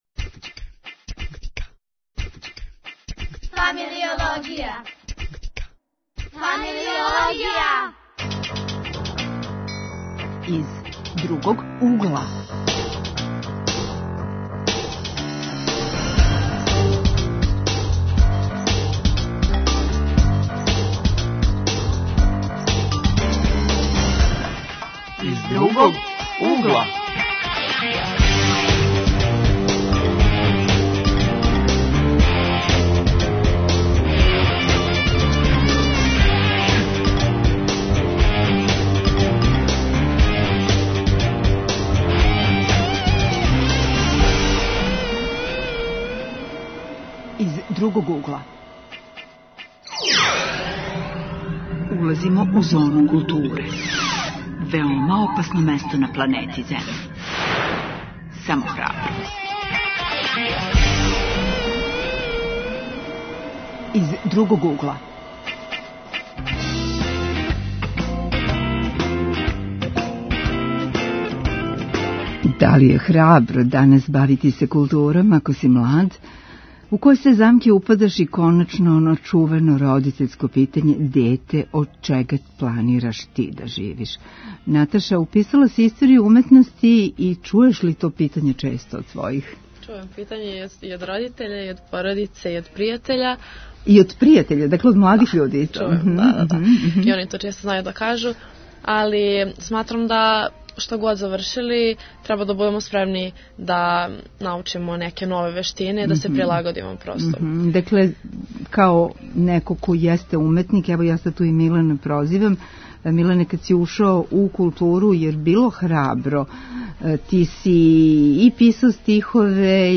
Гост у студију долази из Новог Сада